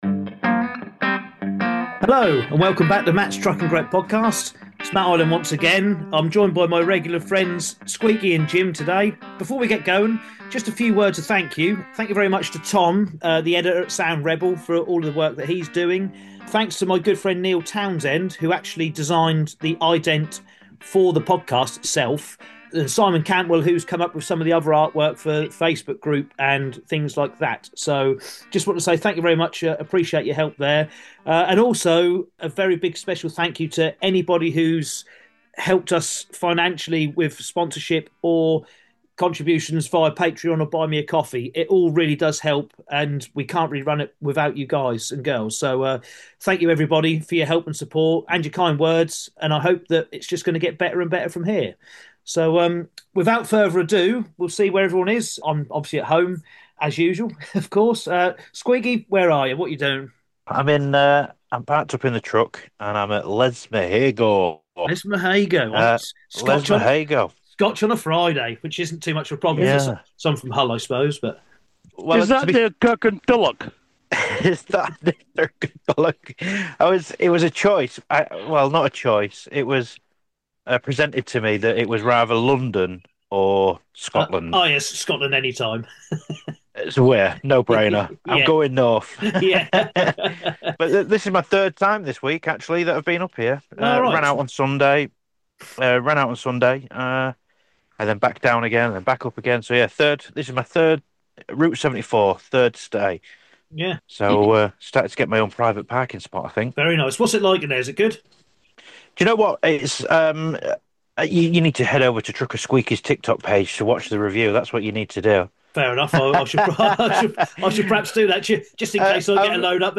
This is the podcast for truck drivers, hosted by and featuring interviews from people in the industry.